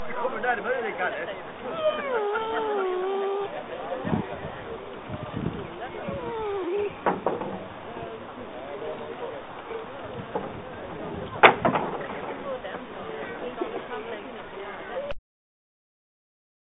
Tävlingen gick av stapeln på Norrköpings Brukshundklubb.
Gnyendet i bakgrunden kommer från Chili, som inte alls ville ligga stilla hos husse när Kenzo och jag var inne på planen...